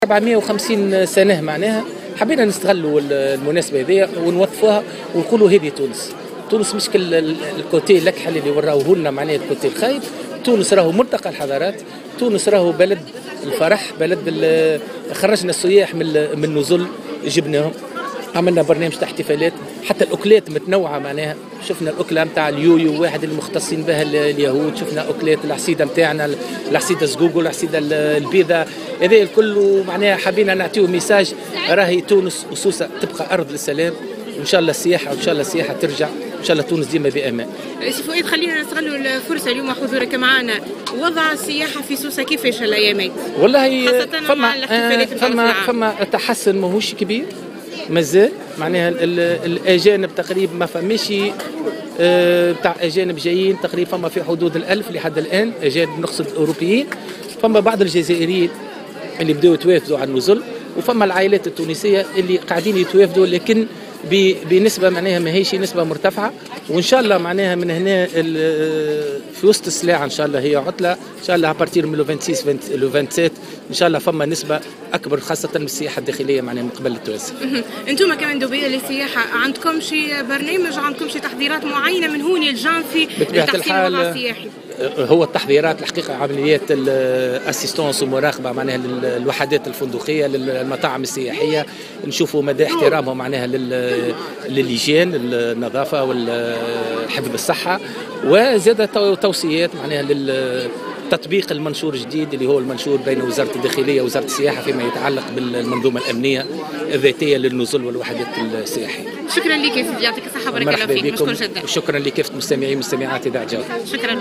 Le délégué régional du tourisme a sousse, Foued Eloued, a déclaré ce jeudi 24 décembre 2015 dans une intervention sur les ondes de Jawhara FM, que le secteur touristique à Sousse commence petit à petit à retrouver sa « bonne santé ».